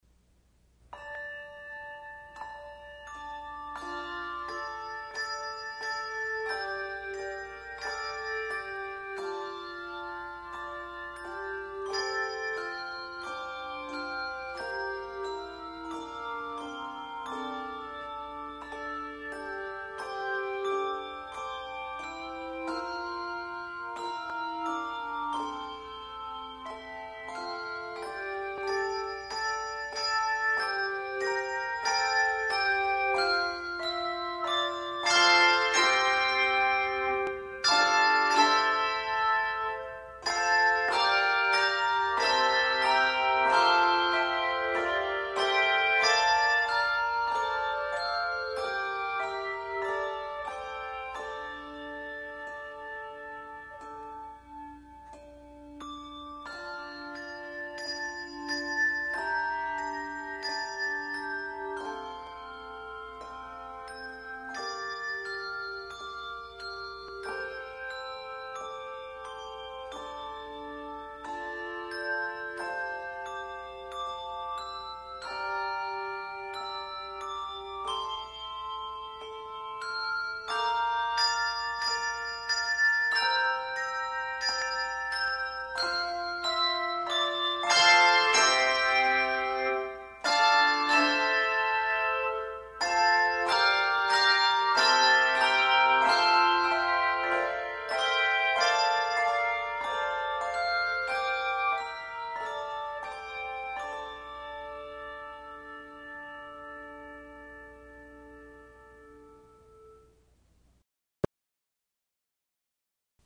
Handbell Quartet
Genre Sacred
No. Octaves 3 Octaves